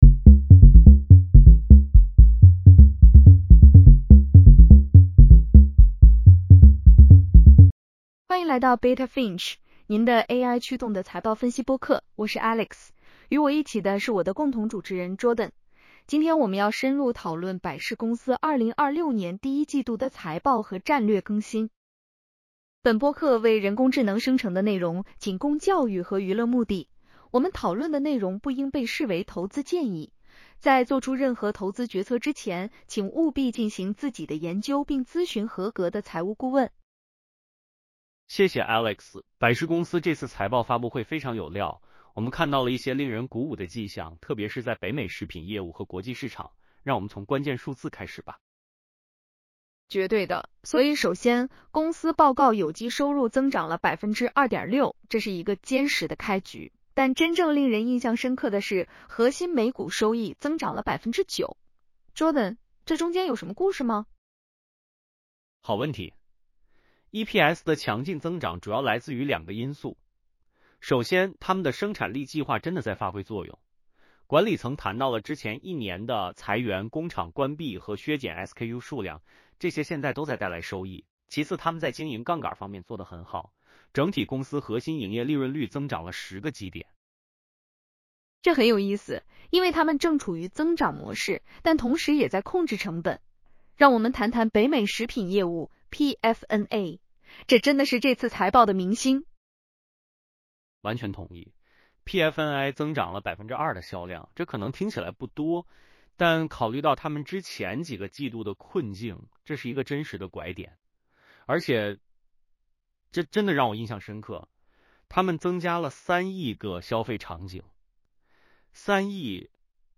本播客为人工智能生成的内容，仅供教育和娱乐目的。